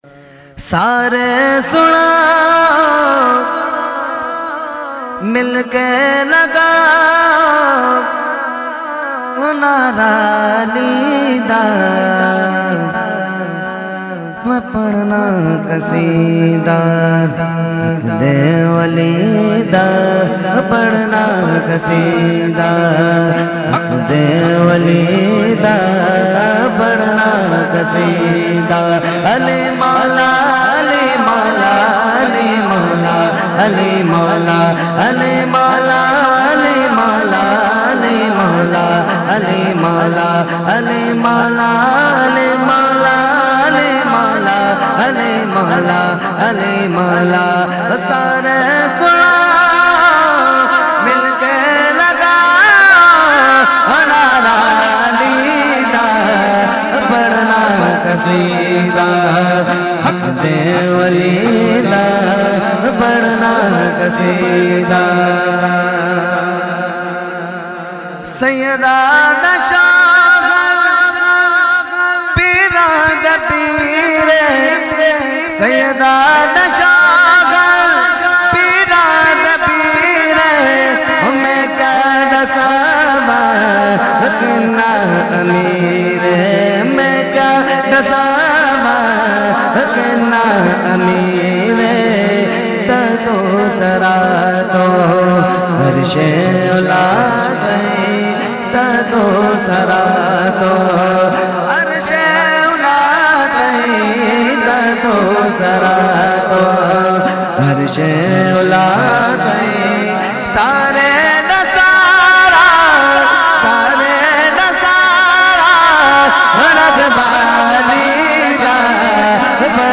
Mehfil e Meelad un Nabi ﷺ Held On 28 October 2020 At Jama Masjid Ameer Hamza Nazimabad Karachi.
Category : Manqabat | Language : UrduEvent : Mehfil e Milad Jamia Masjid Ameer Hamza 2020